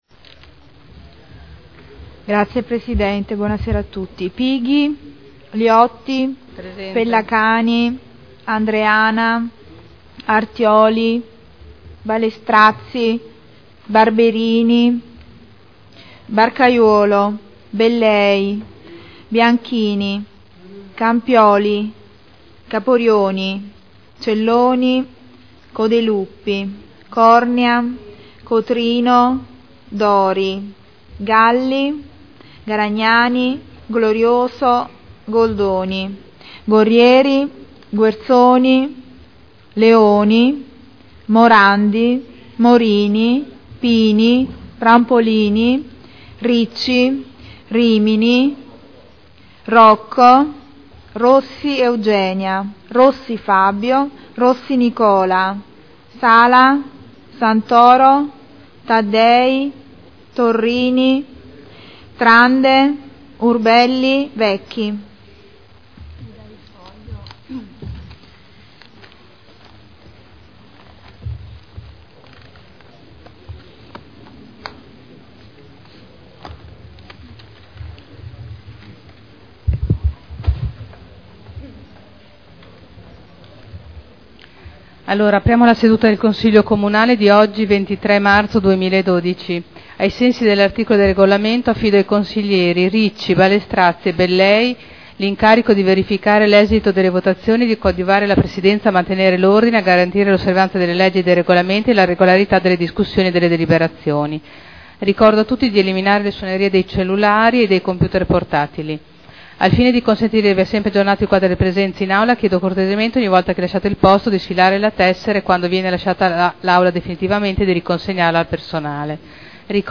Apertura del Consiglio Comunale. Appello.
Segretario Generale